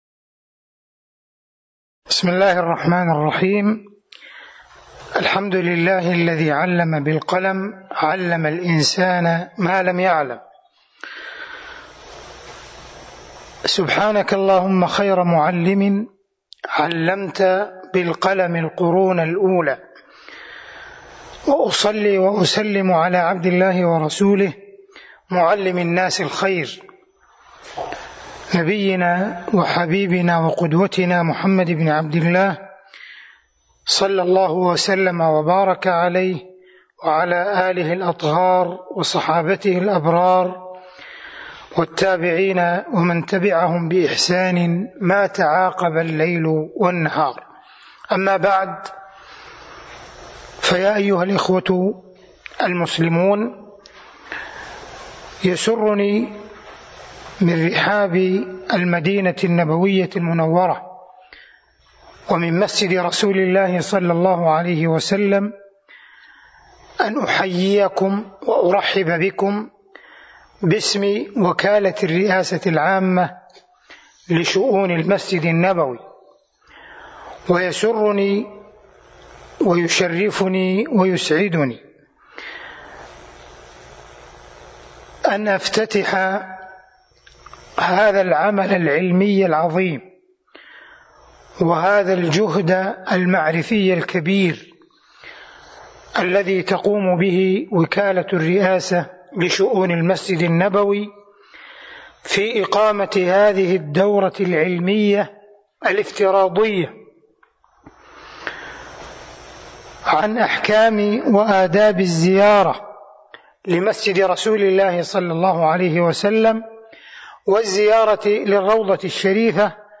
كلمة افتتاح الدورة أحكام وآداب الزيارة في ظل جائحة كورونا
المكان: المسجد النبوي